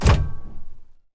train-door-close-2.ogg